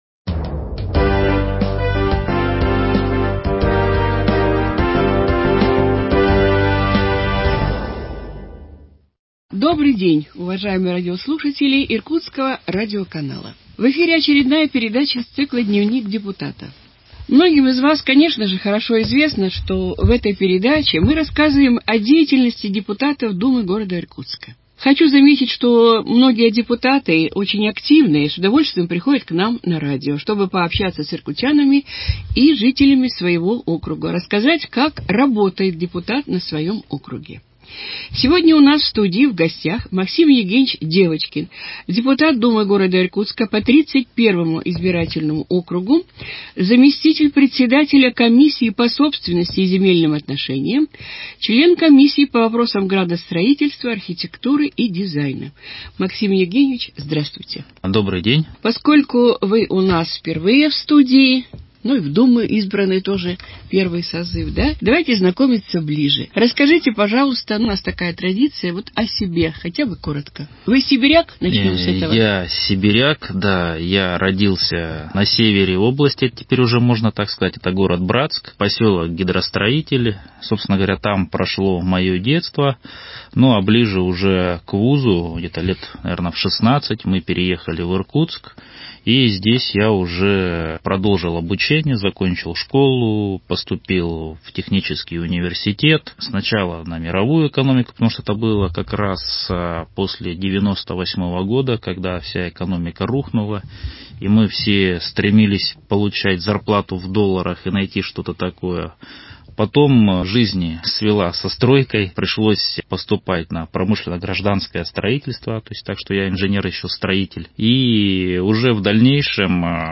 ведет беседу с депутатом Думы г. Иркутска по избирательному округу № 31 Максимом Евгеньевичем Девочкиным.